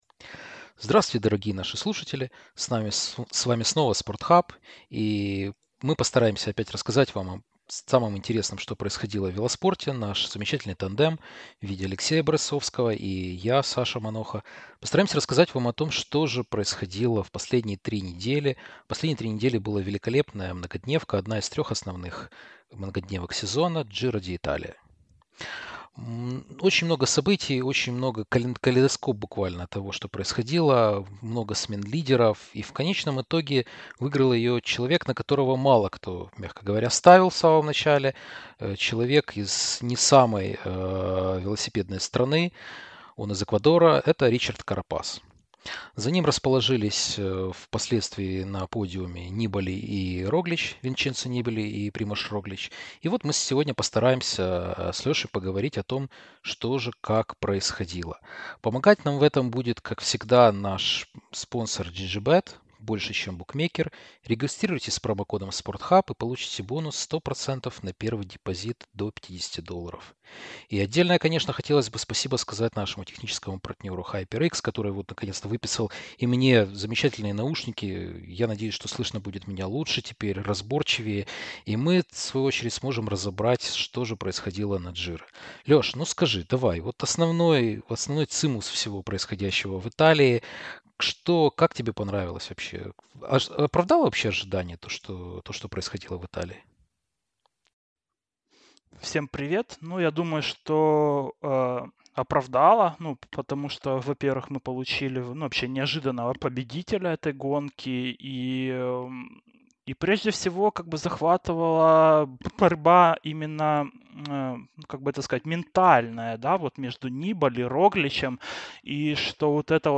Мы записываем наши подкасты на гарнитуры HyperX Cloud Mix , а также с помощью микрофона HyperX Quadcast , который нам любезно предоставили наши друзья с HyperX, за что мы очень благодарны!